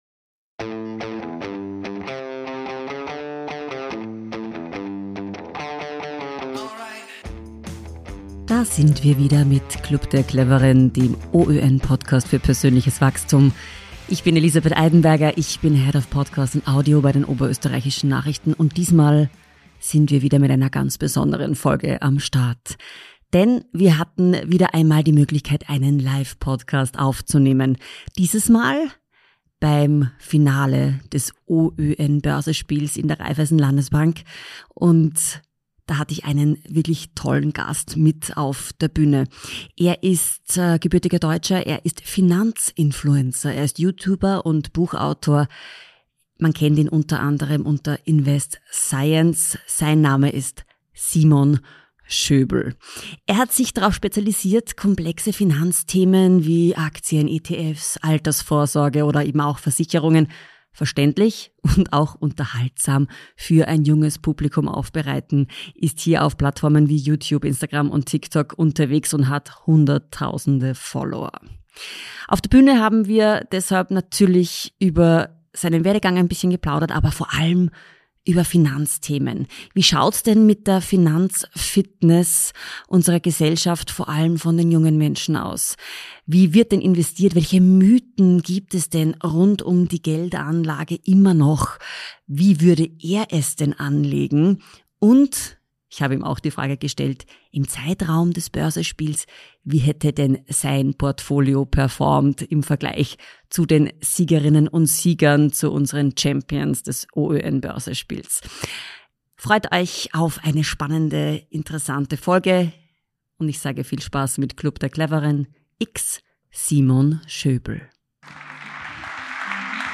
Im Live-Podcast beim Finale des OÖN-Börsespiels gab er Einblicke in die Finanzwelt von heute und wie schlaues Investieren für jeden möglich ist.